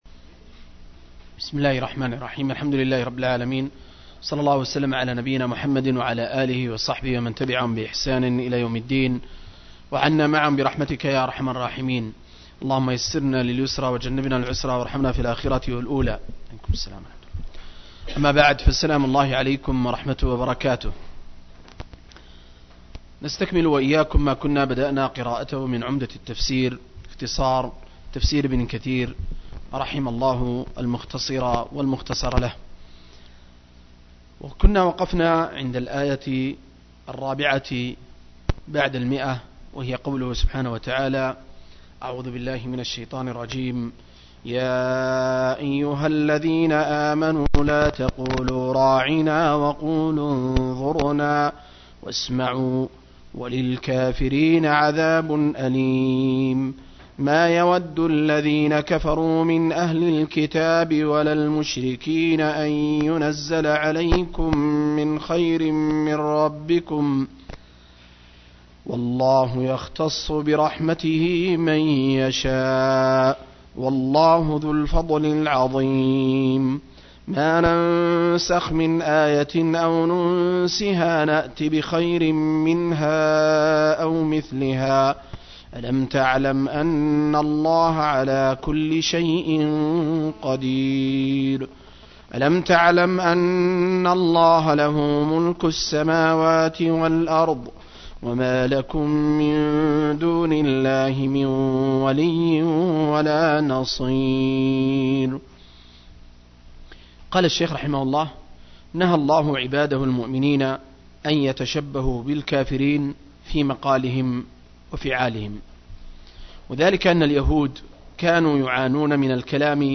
024- عمدة التفسير عن الحافظ ابن كثير – قراءة وتعليق – تفسير سورة البقرة (الآيات 104-107)